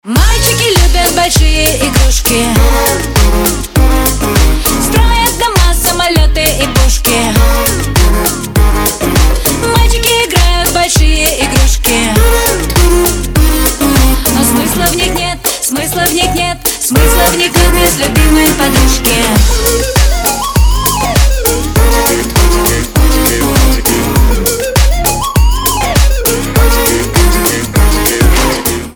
• Качество: 320, Stereo
поп
громкие
женский вокал
dance